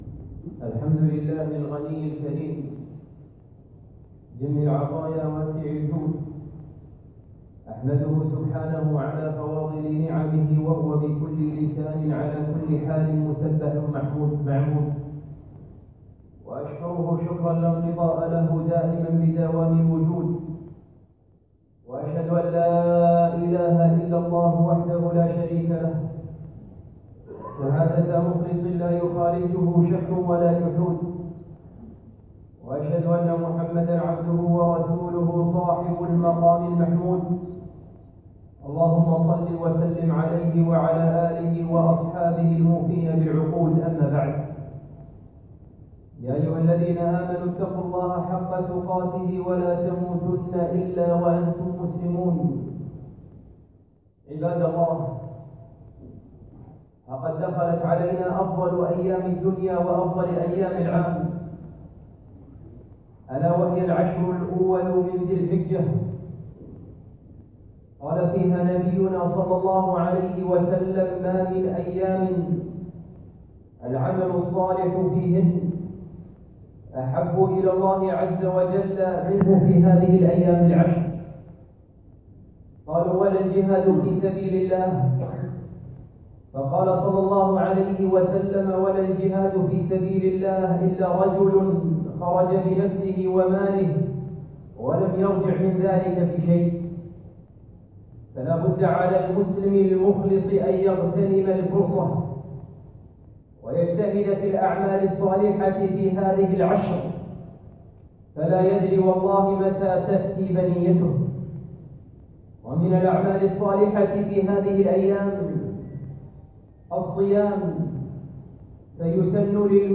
خطبة - من أحكام الأضحية